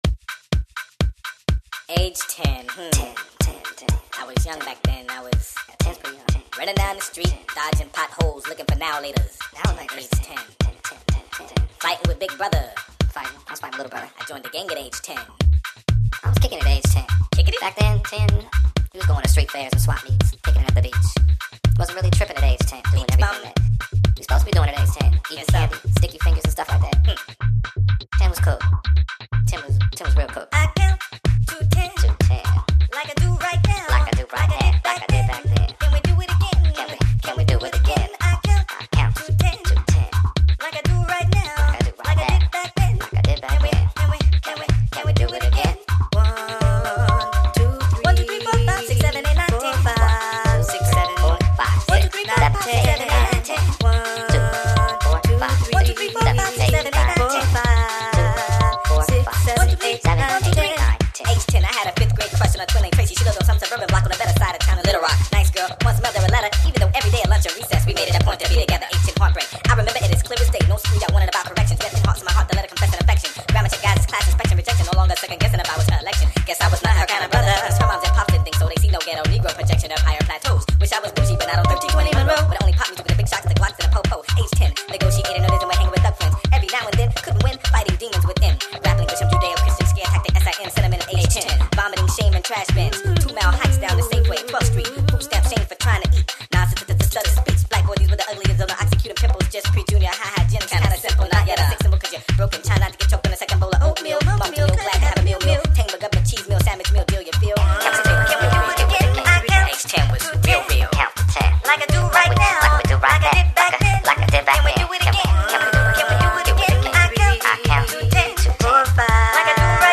A tech-house remix
hip-hop groove